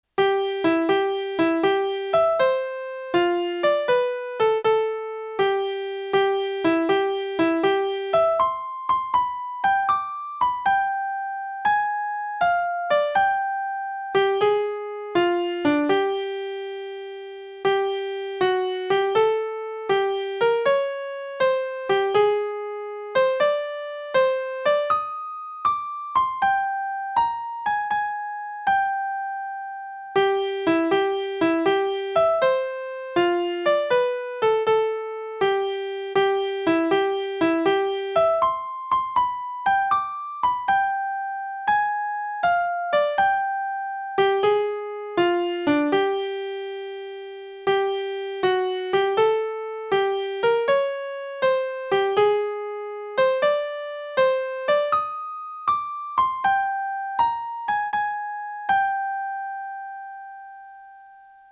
Помогите, пожалуйста, определить название одной мелодии, по нижеприведённому примерному наигрышу! (Как всегда у меня - "схематично", одной рукой:)
Для быстроты возможного определения, темп увеличил: